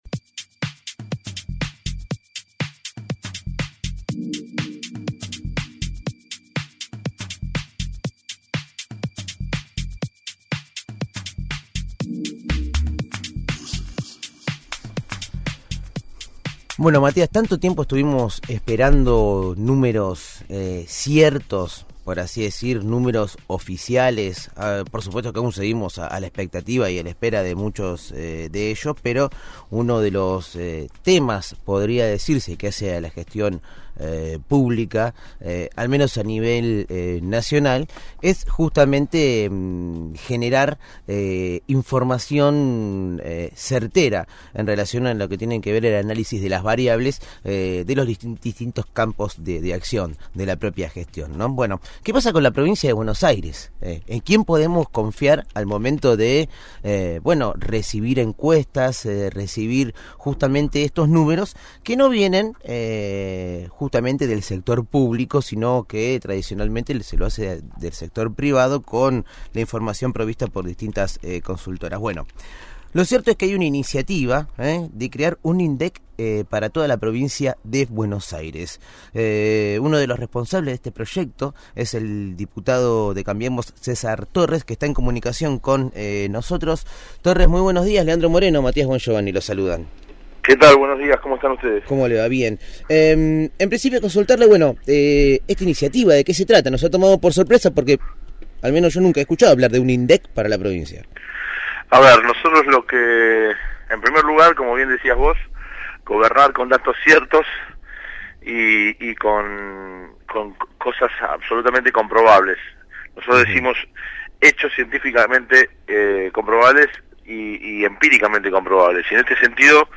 César Torres, diputado provincial por Cambiemos, dialogó con el equipo de «El hormiguero» sobre el  proyecto que presentó para crear el Instituto Provincial de Estadística y Censos (IPEC) con el fin de mejorar la planificación.